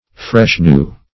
Fresh-new \Fresh"-new`\, a.